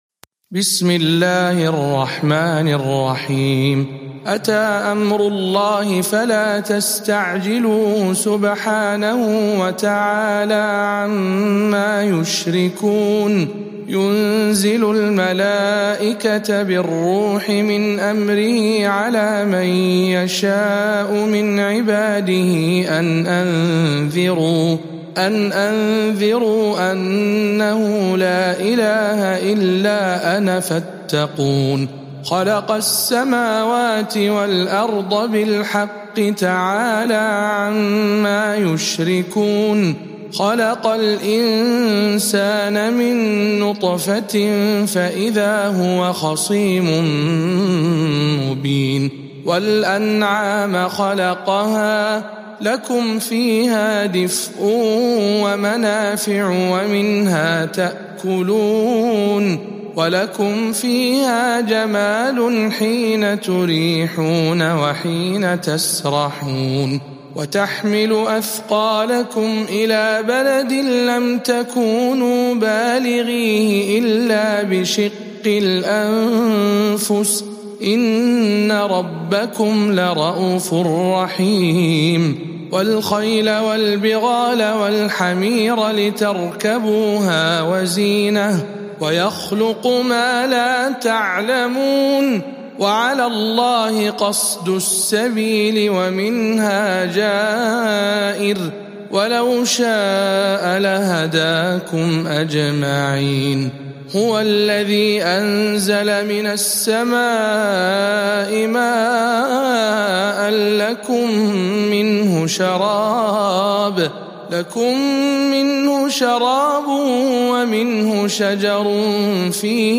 سورة النحل برواية الدوري عن أبي عمرو